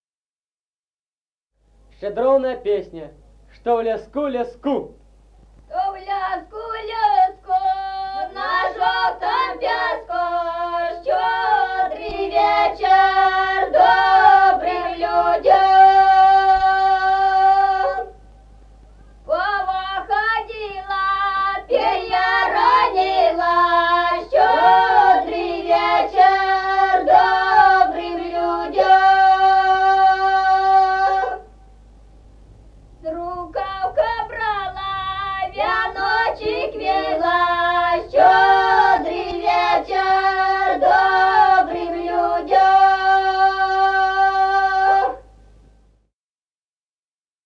Музыкальный фольклор села Мишковка «Что в леску, леску», щедровная.